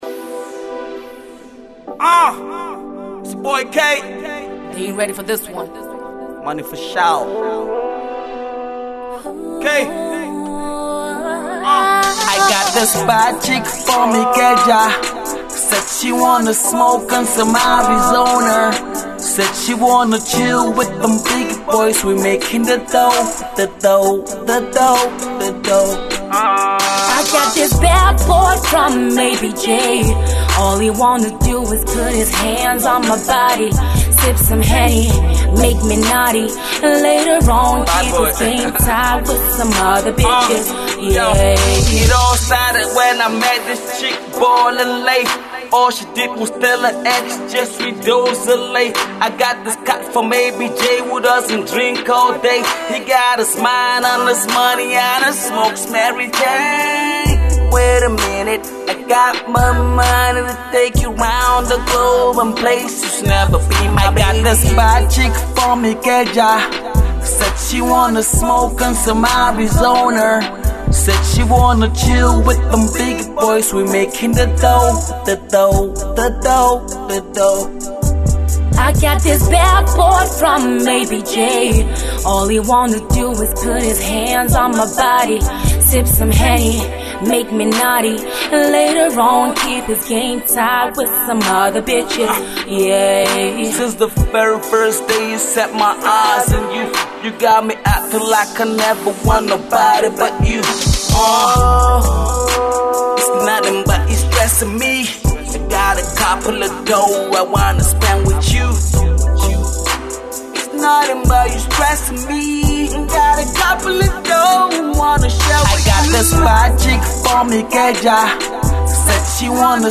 The female vocalist pon this tune got me like woooh!!